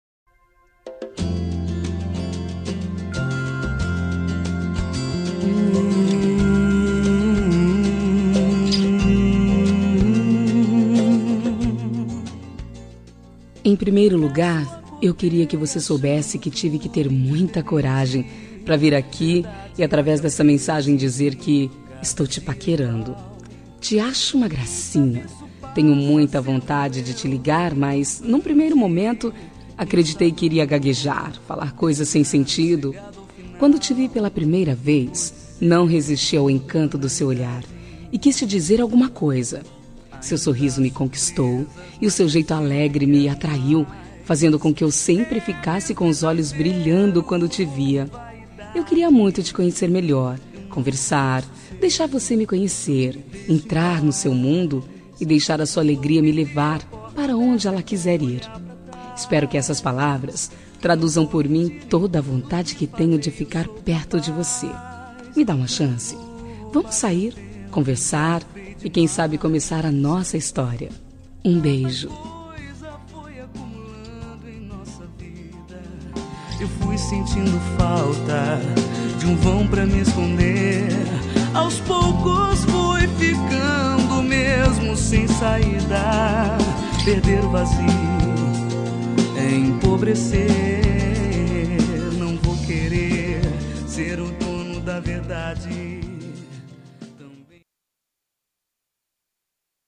Telemensagem de Conquista – Voz Feminina – Cód: 140118